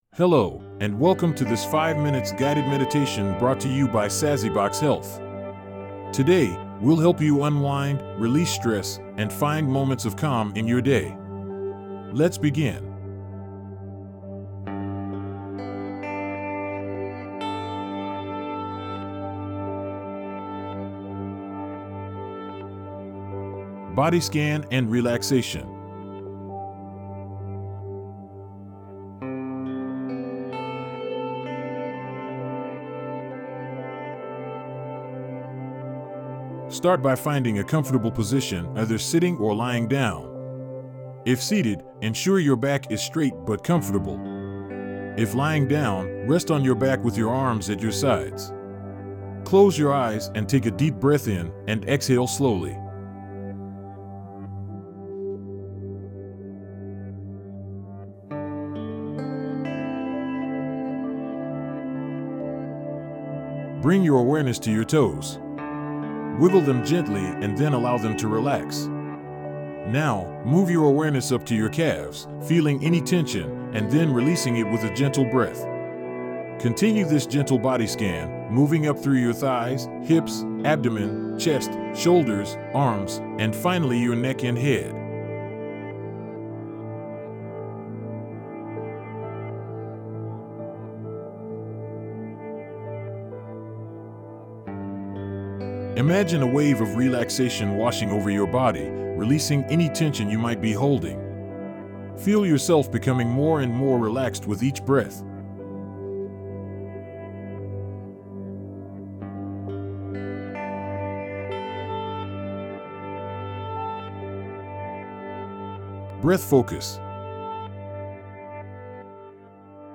5-minute-guided-meditation-audio-2.mp3